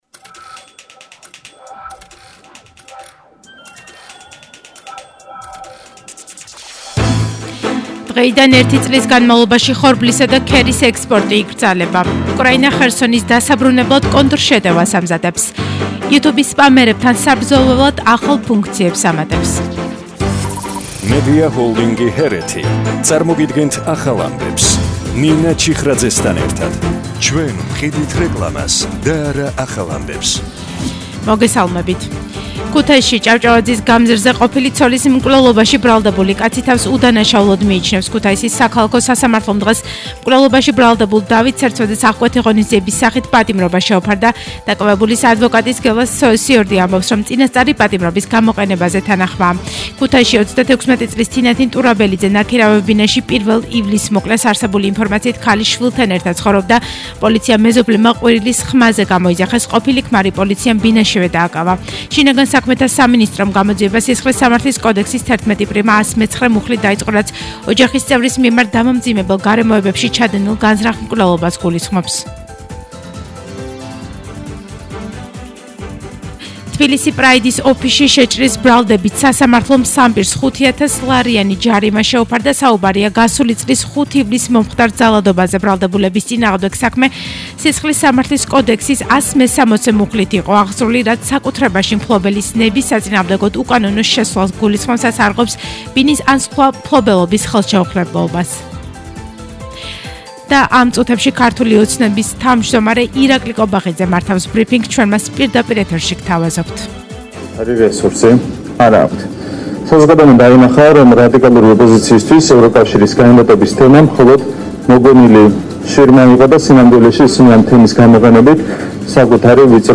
ახალი ამბები 14:00 საათზე – 04/07/22